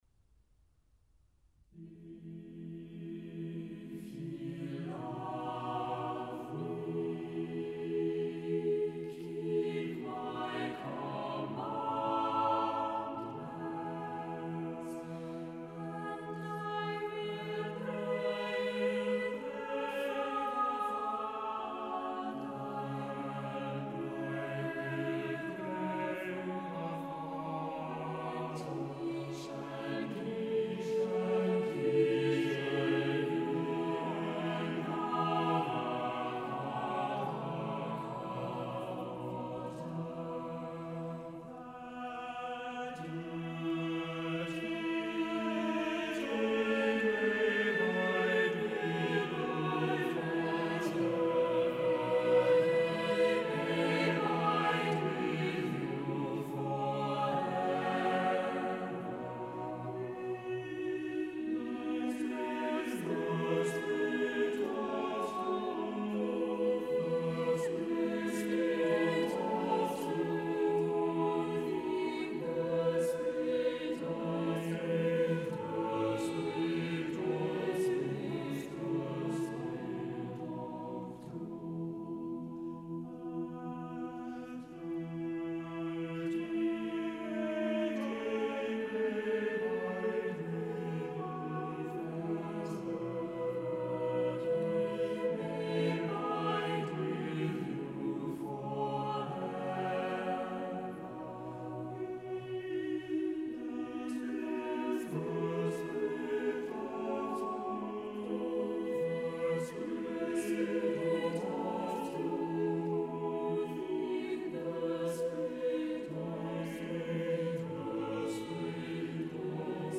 Listen to the Tallis Scholars perform "If Ye Love Me" by Thomas Tallis.